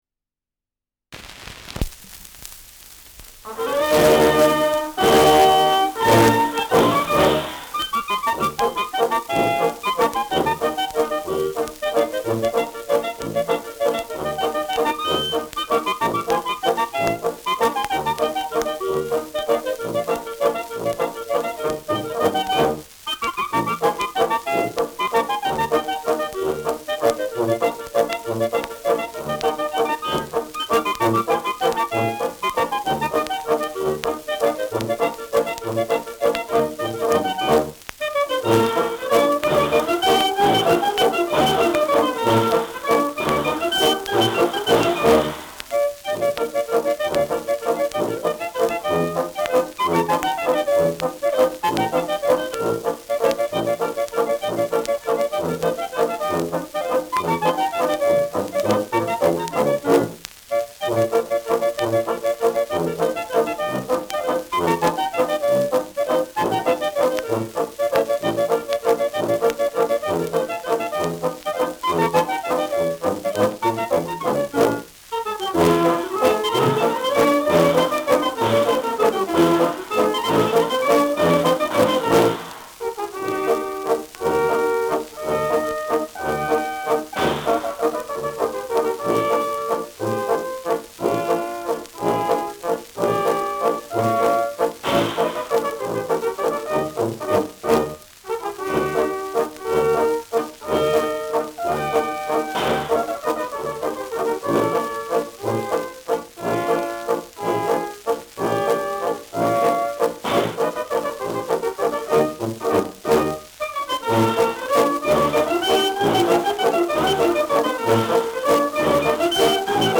Schellackplatte
Stärkeres Grundrauschen : Verzerrt an lauten Stellen : Durchgehend leichtes bis stärkeres Knacken
Kapelle Durlhofer (Interpretation)